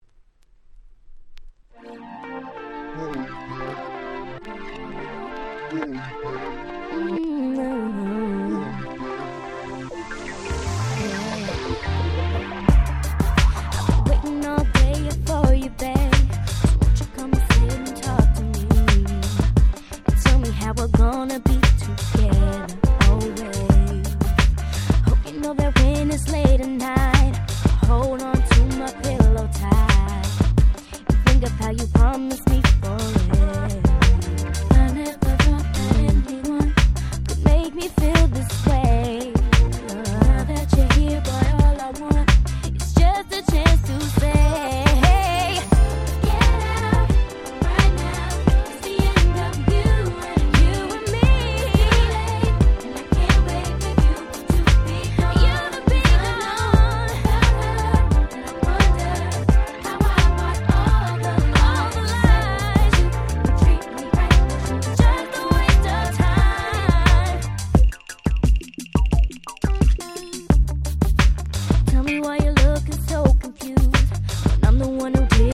※一部試聴ファイルは別の盤から録音してございます。
04' Smash Hit R&B !!
可愛いVocalが堪りません！